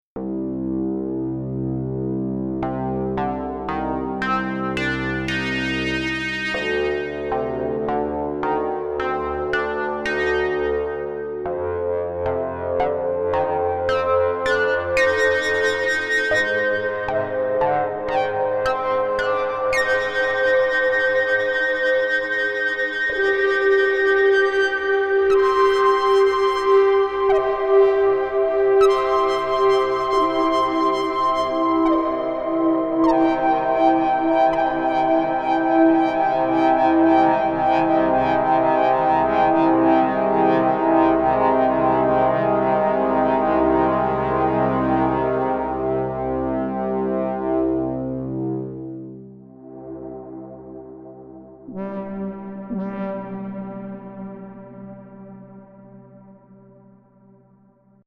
Простейший меандр, BP фильтр, резонанс, дисторшн и чуток ревера.